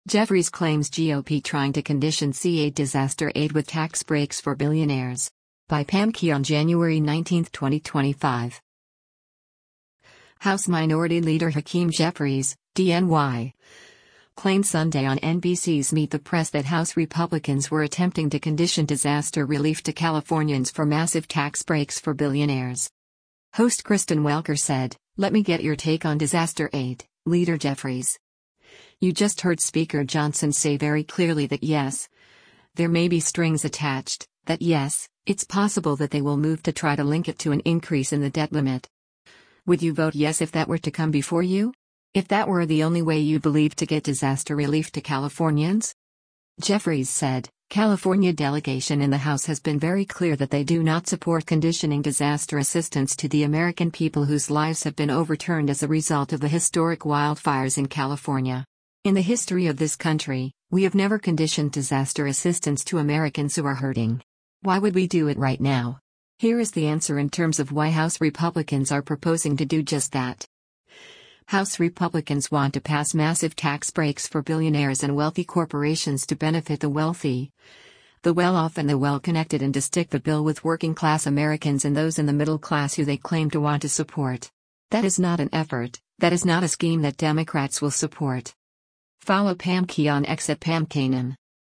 House Minority Leader Hakeem Jeffries (D-NY) claimed Sunday on NBC’s “Meet the Press” that House Republicans were attempting to condition disaster relief to Californians for “massive tax breaks for billionaires.”